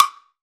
stick perc.wav